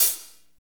HAT F S L0JR.wav